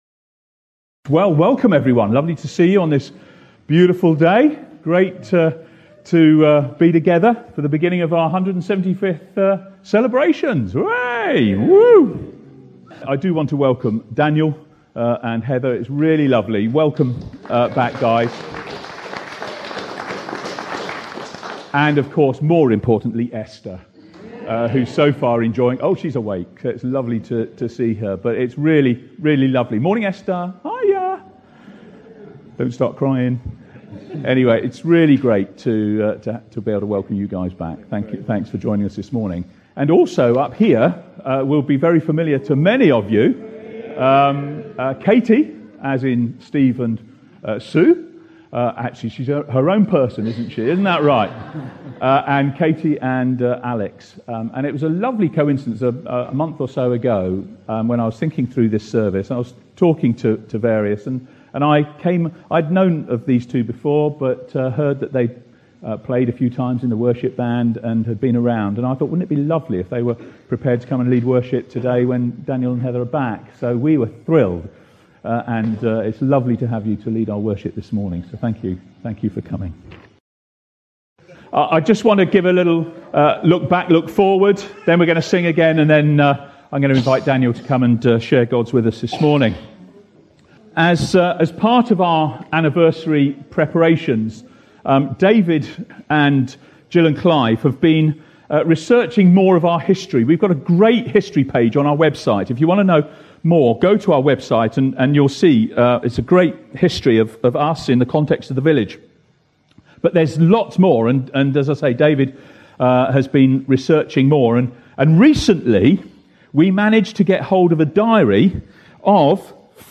On the first Sunday in June we started to celebrate our 175th Anniversary – 1843 to 2018.